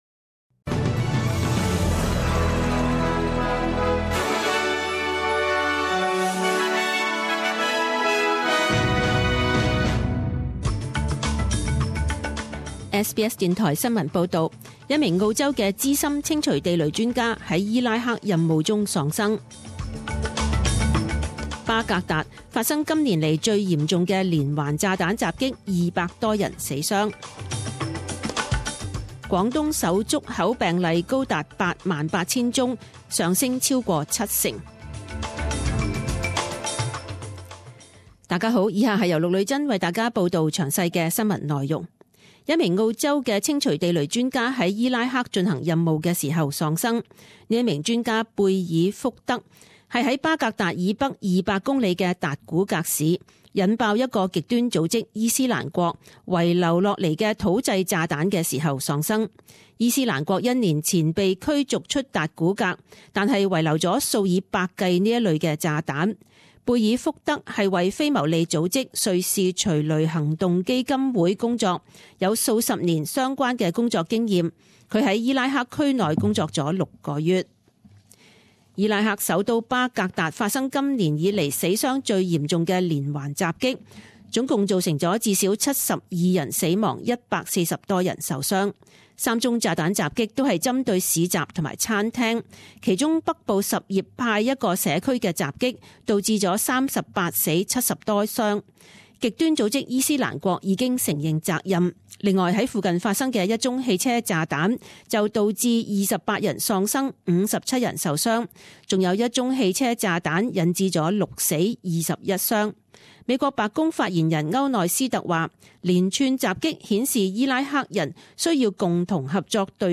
10am News Bulletin 18.05.2016
Details News Bulletins